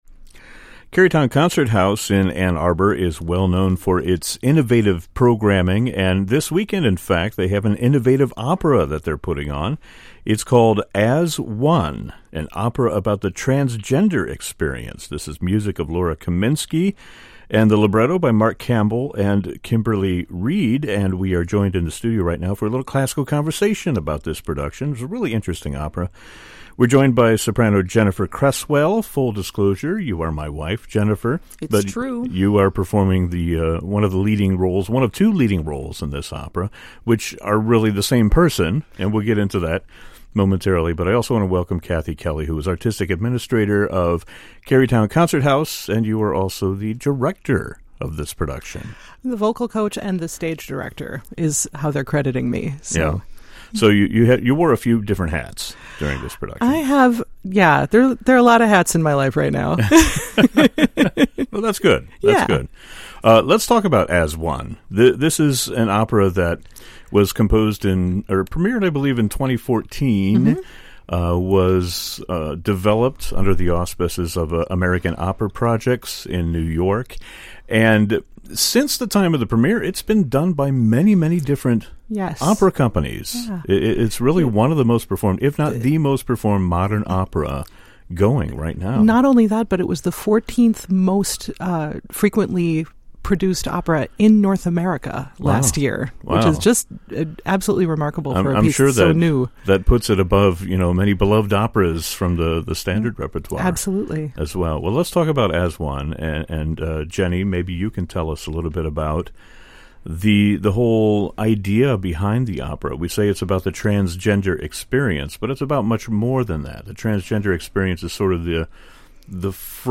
(Note: due to copyright, musical selections have been edited out of the podcast version of this episode)